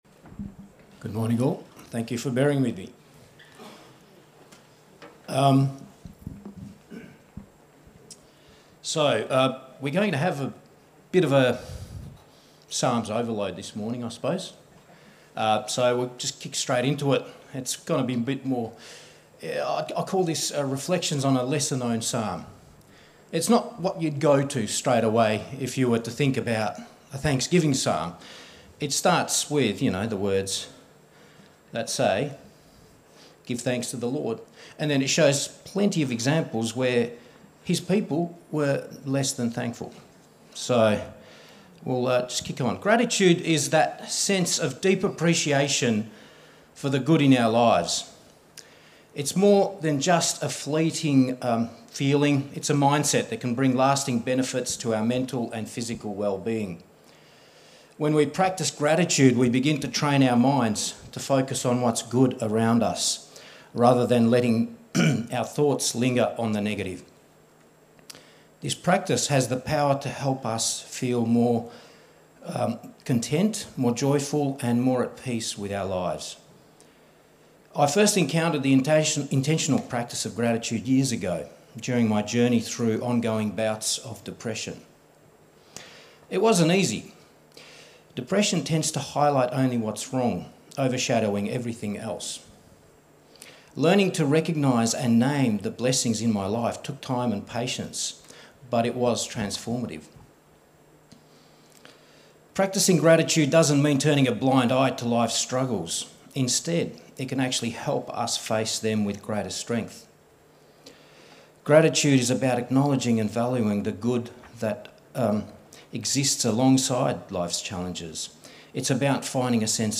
A message from the series "Songs of thanksgiving."